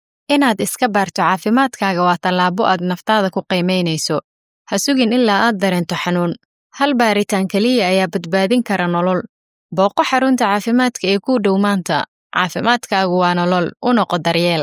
I’m a native Somali and Swahili voice-over artist with a warm, clear, and professional voice.
Warm